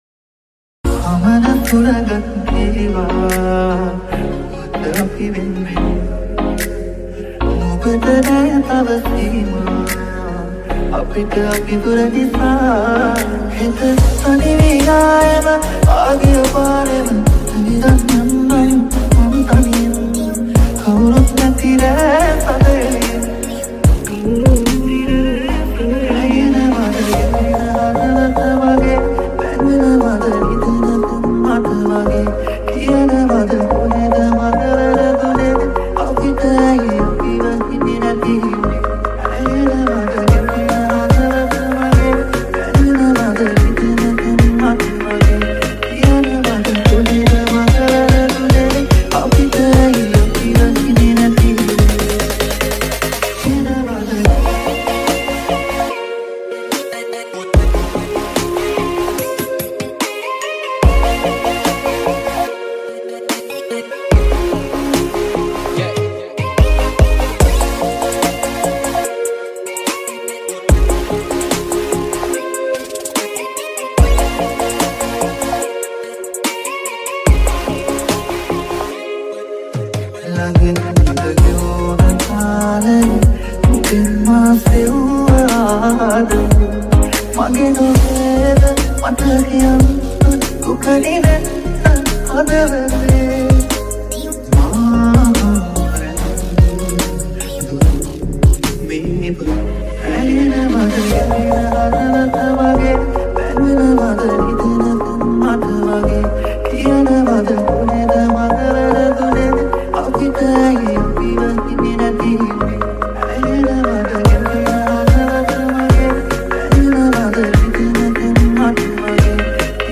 High quality Sri Lankan remix MP3 (2.5).